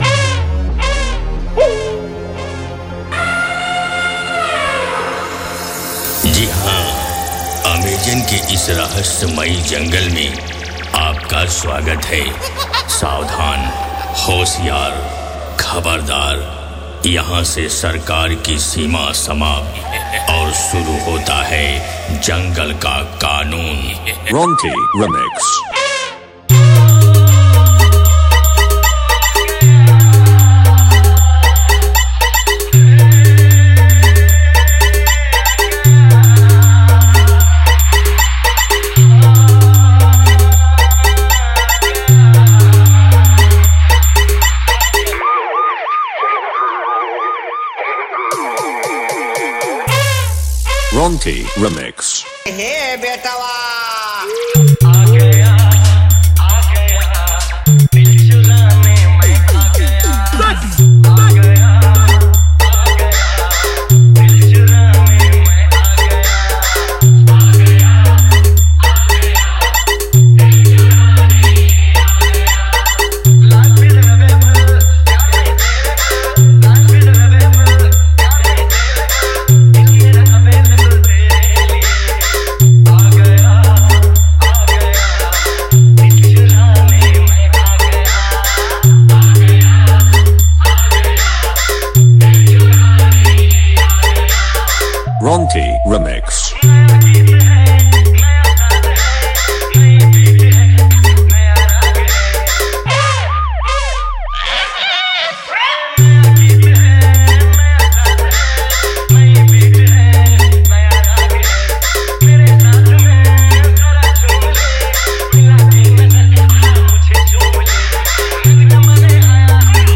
New Style 1 Step Long Humming Dance Bass Mix 2024